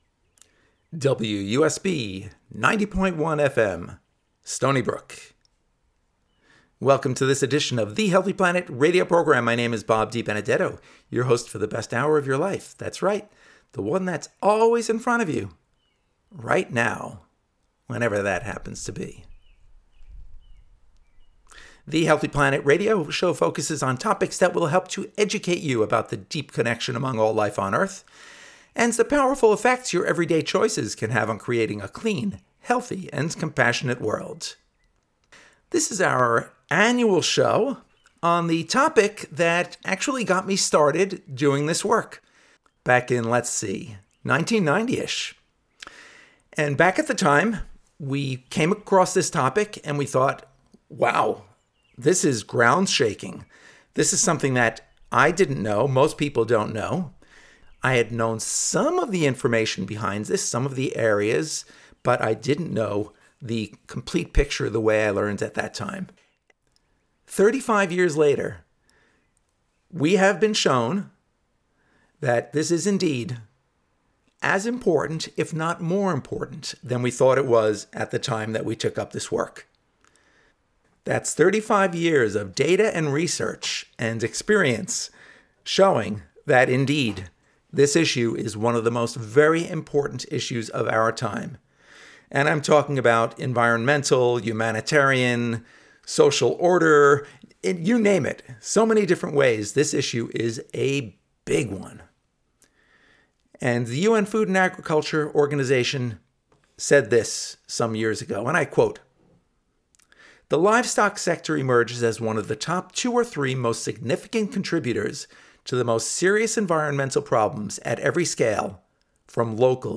The Healthy Planet Radio Show. The Healthy Planet Radio Show has been airing on WUSB, 90.1 FM since January of 2007 and can be heard on the second Friday of each month at 6pm, immediately following Democracy Now! News.